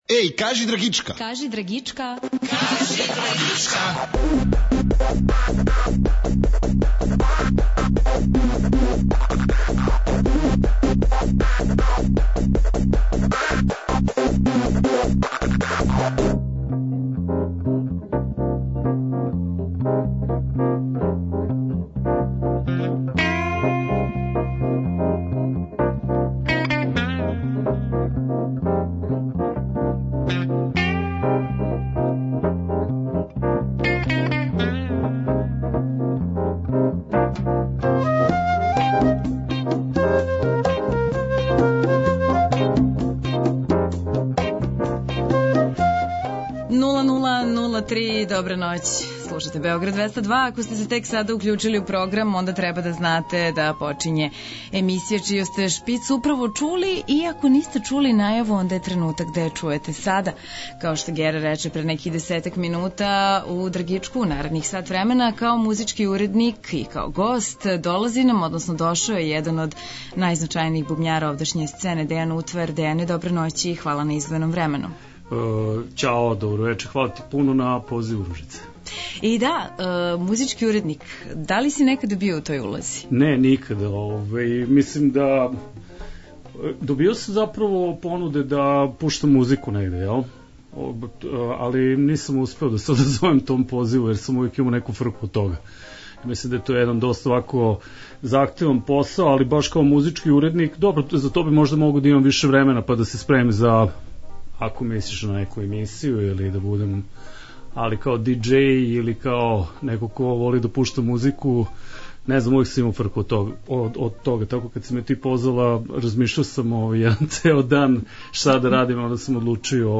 А ми вас подсећамо на то да ћемо музику емотивати и са плоча, директно у програм.